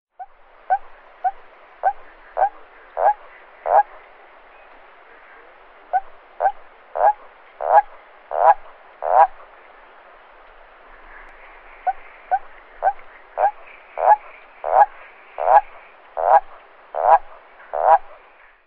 Rospo comune
Bufo bufo
Canto-Rospo-comune.mp3